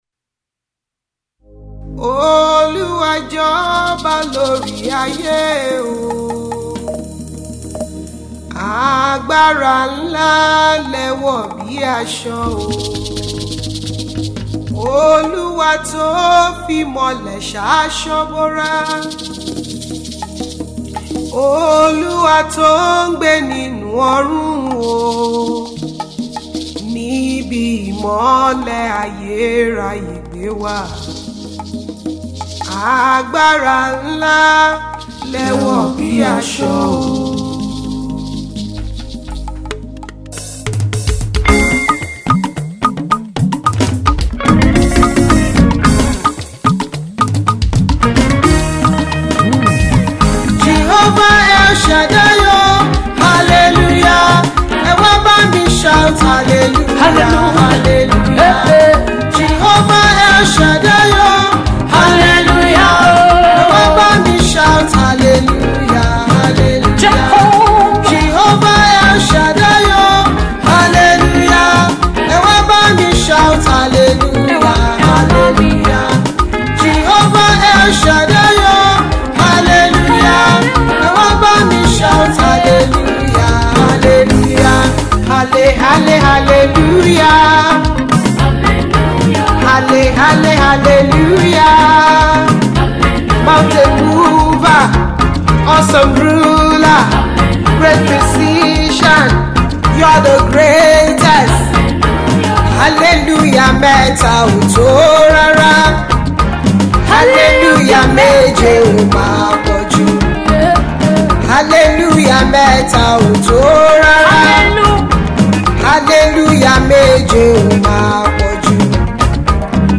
An upbeat Highlife tune garnished with African percussion
Indigenous praise medley